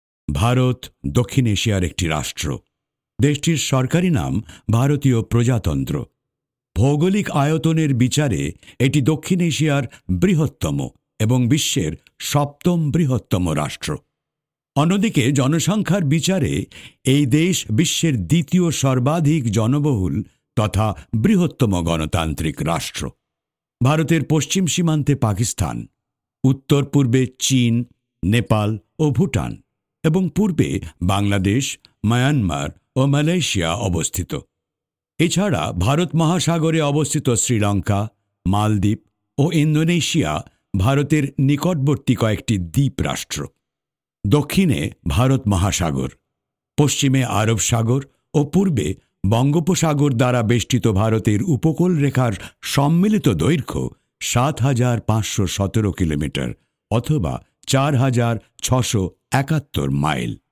纪录片【磁性浑厚